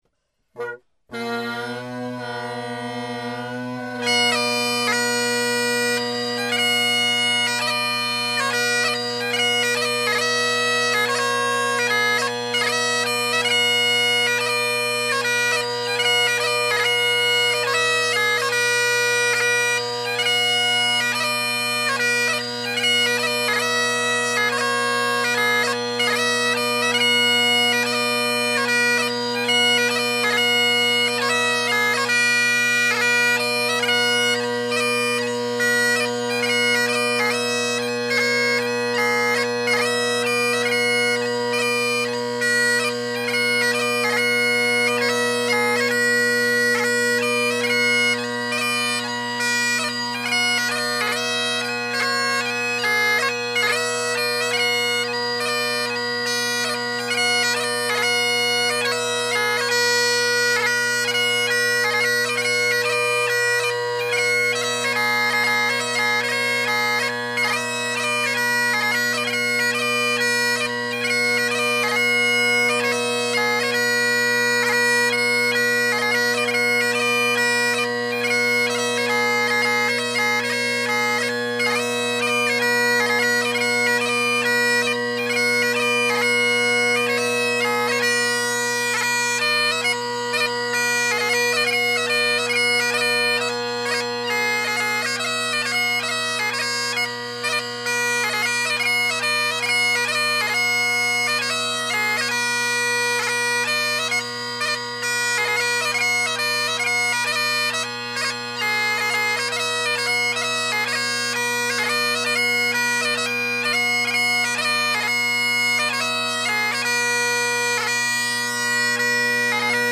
Drone Sounds of the GHB, Great Highland Bagpipe Solo
2. Do not use variable bit rate MP3 recording mode on your Zoom H2. It will sound bad.